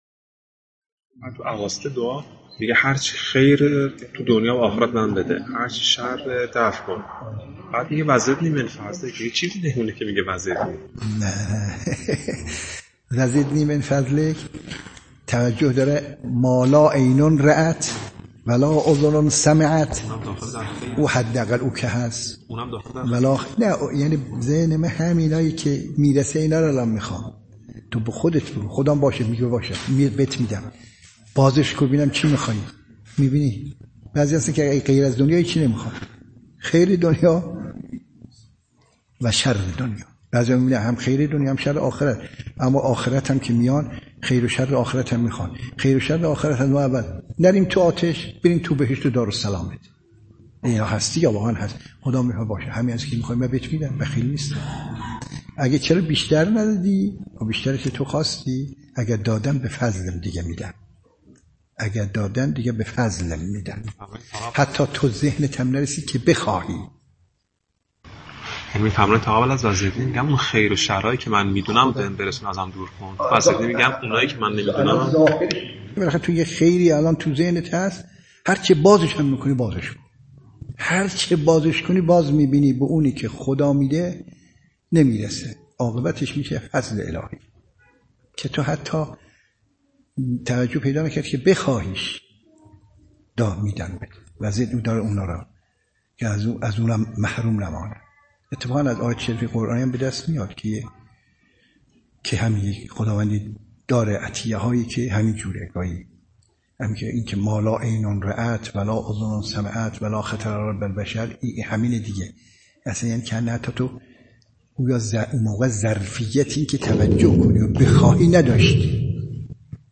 پرسش‌ها و پاسخ‌های بعد از جلسه۵۹ یک‌شنبه (۹بهمن۱۴۰۱)